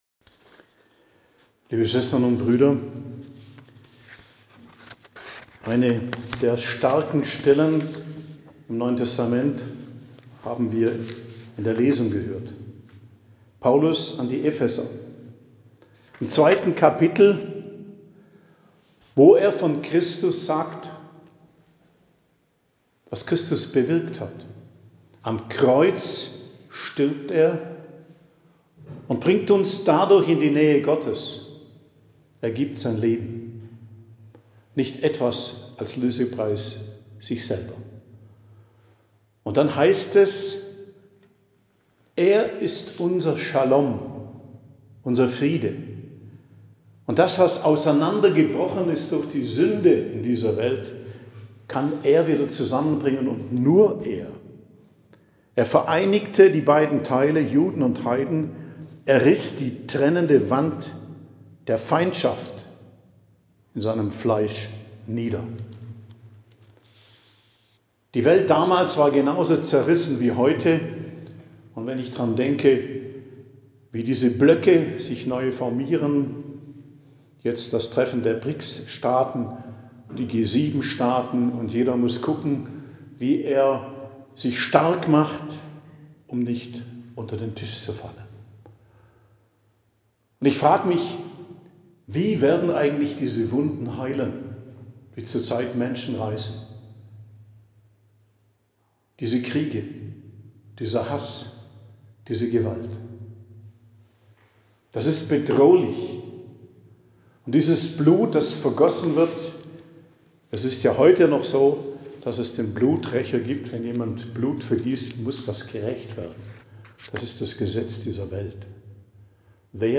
Predigt am Dienstag der 29. Woche i.J. 22.10.2024